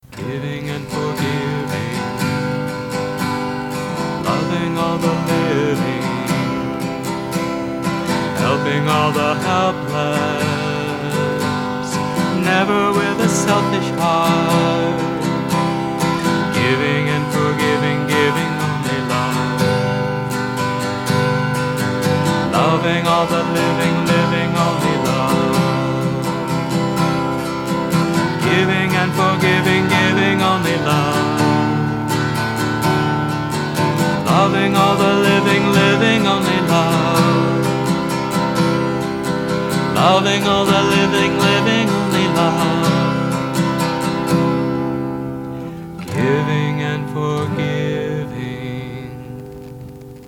1. Devotional Songs
Major (Shankarabharanam / Bilawal)
8 Beat / Keherwa / Adi
2 Pancham / D
6 Pancham / A
Lowest Note: G2 / E
Highest Note: R2 / D (higher octave)